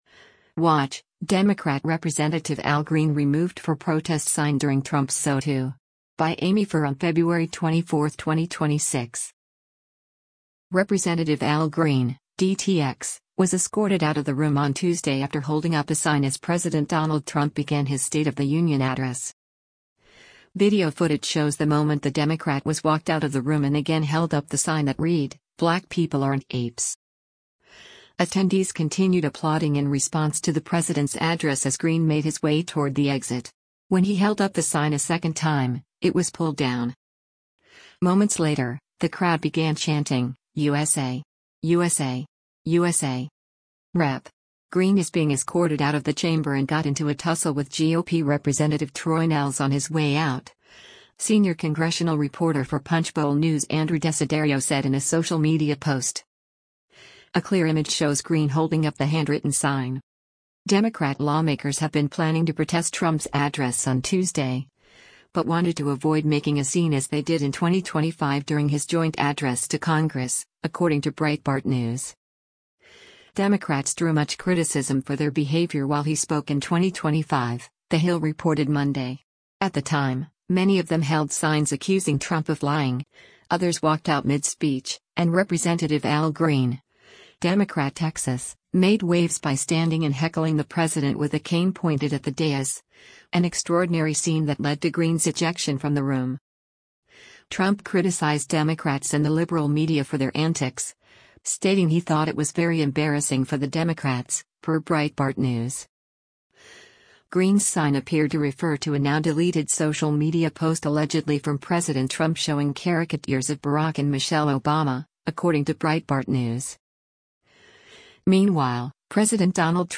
Rep. Al Green (D-TX) was escorted out of the room on Tuesday after holding up a sign as President Donald Trump began his State of the Union address.
Attendees continued applauding in response to the president’s address as Green made his way toward the exit.
Moments later, the crowd began chanting, “USA! USA! USA!”